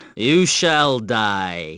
you shall die sound effects